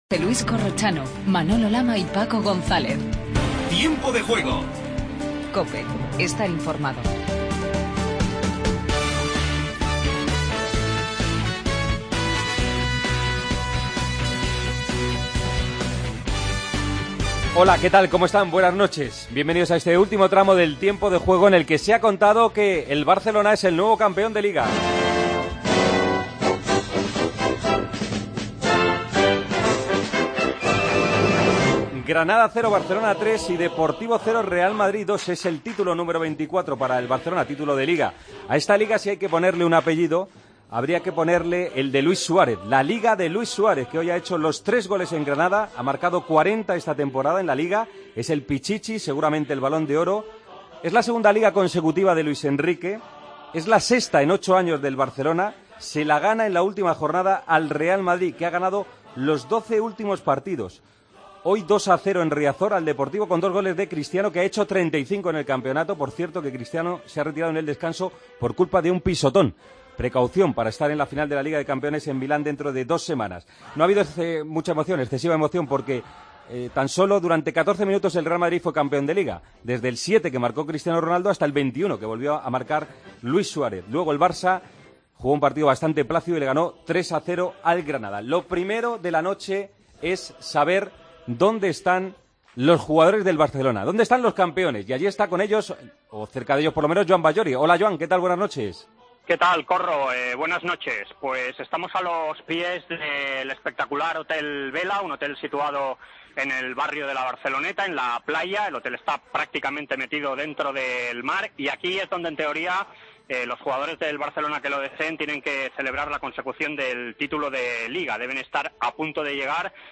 Escuchamos a Piqué, Iniesta, Suárez. Entrevista a Tiago.